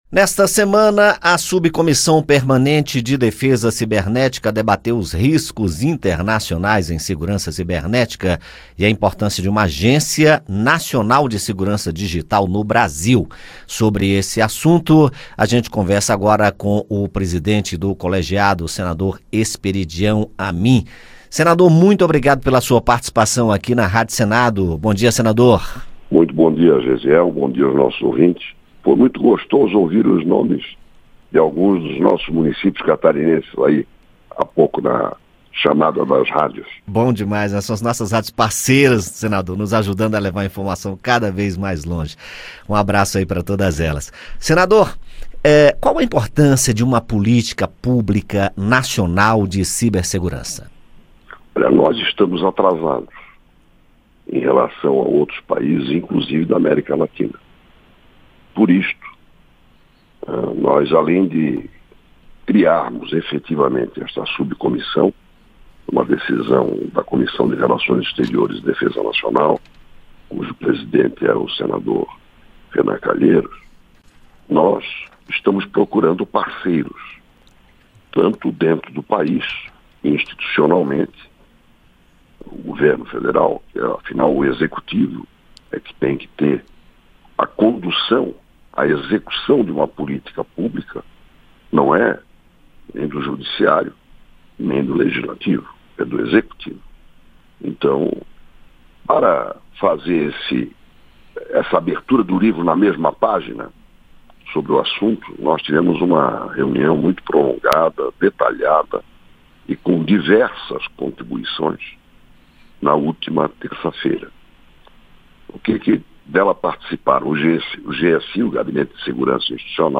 Entrevista: Esperidião Amin discute política nacional de cibersegurança
A Subcomissão Permanente de Defesa Cibernética promoveu nesta semana um debate sobre os riscos internacionais na área de segurança cibernética e a importância de uma agência nacional de segurança digital no Brasil. O presidente da subcomissão é o senador Esperidião Amin (PP-SC), que concedeu entrevista à Rádio Senado para tratar desses assuntos.